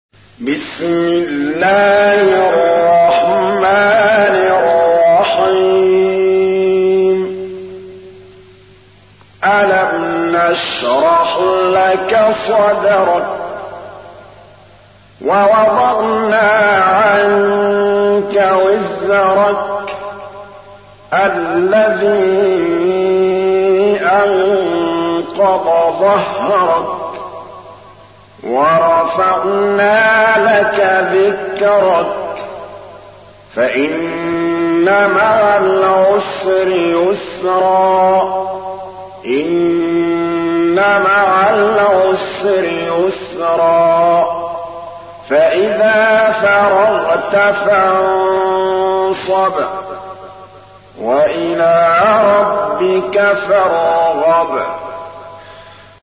تحميل : 94. سورة الشرح / القارئ محمود الطبلاوي / القرآن الكريم / موقع يا حسين